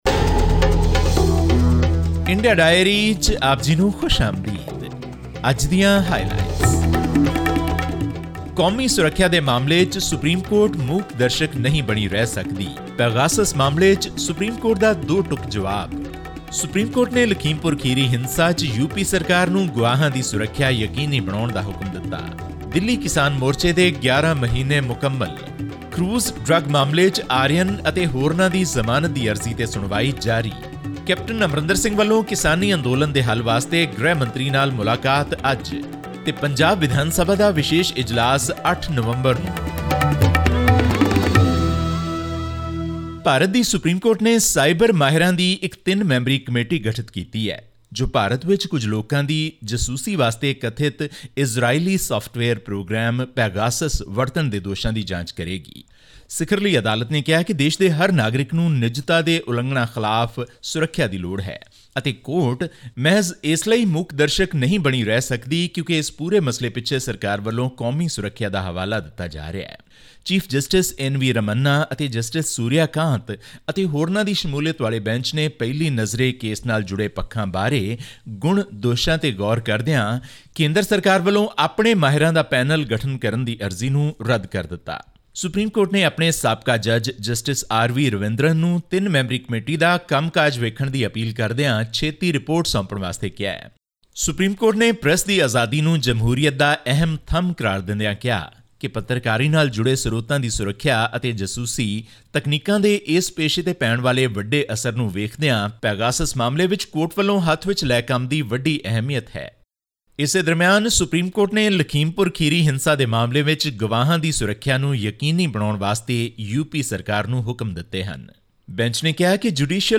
23-year-old Aryan Khan was granted bail 26 days after being arrested for allegedly doing recreational drugs at a party on a Goa-bound cruise. All this and more in our weekly news segment from India.